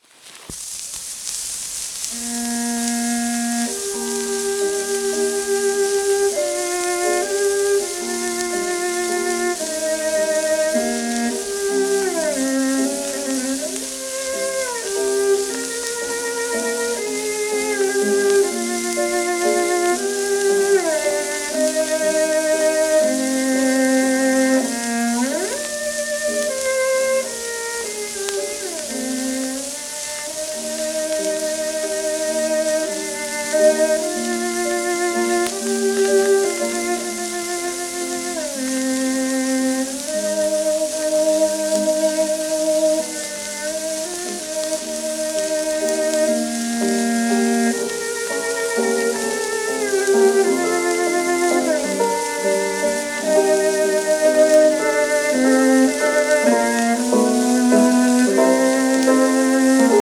w/ピアノ
録音年不詳 80rpm
旧 旧吹込みの略、電気録音以前の機械式録音盤（ラッパ吹込み）